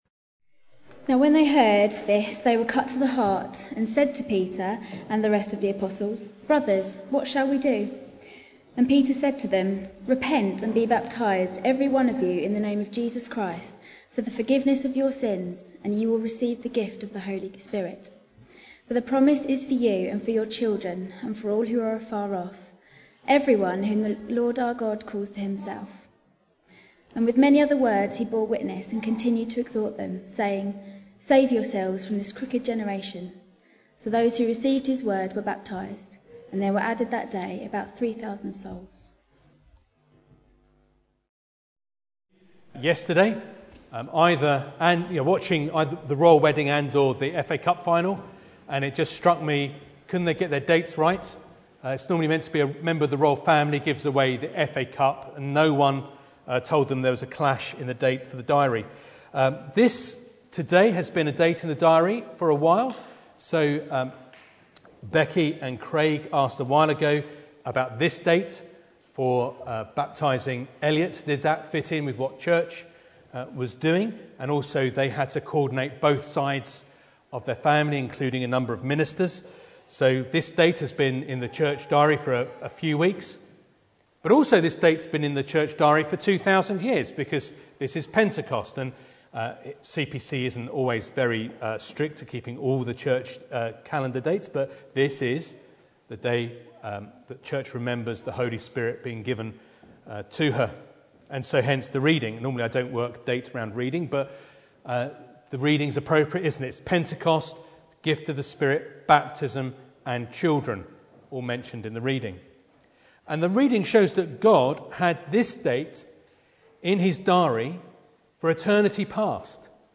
Acts 2:37-41 Service Type: Sunday Morning Bible Text